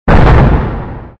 ExplodeSmall.wav